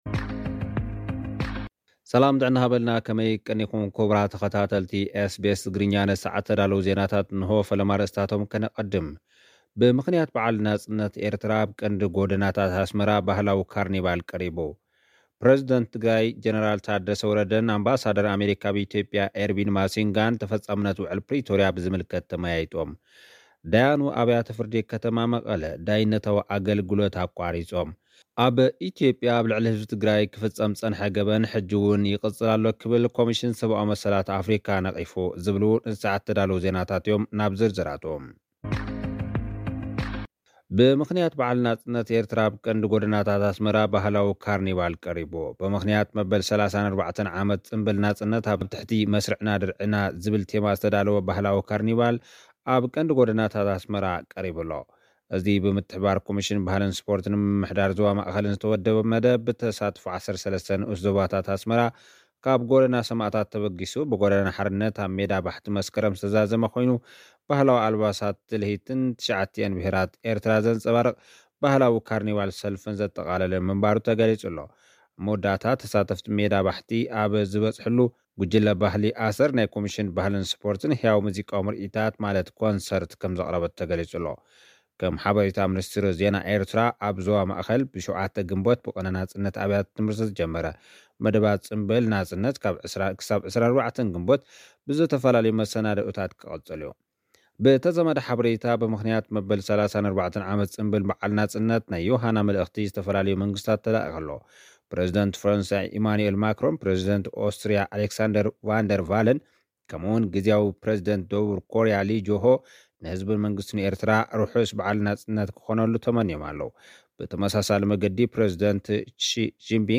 ጸብጻባት ዜና